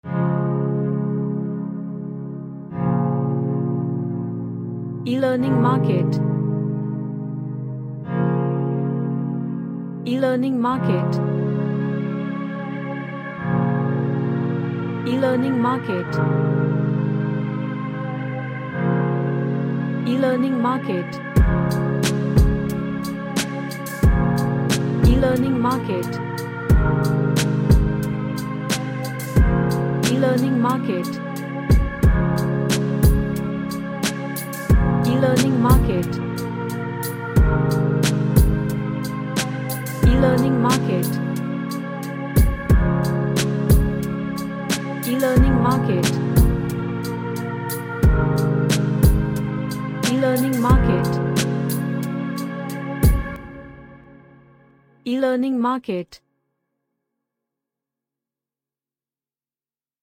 A relaxing bassy chords with nice groove.
Relaxation / Meditation